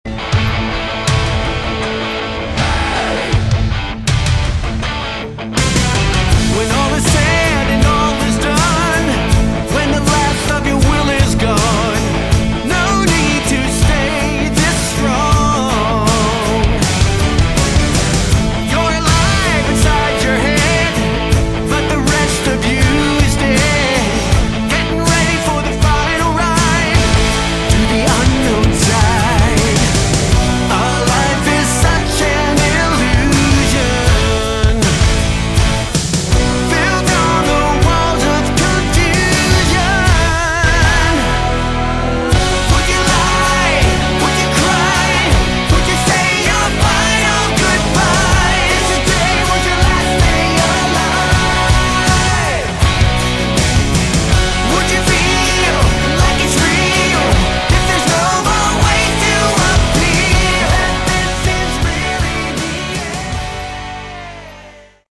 Category: Melodic Rock
guitars, bass, keyboards, backing vocals
lead vocals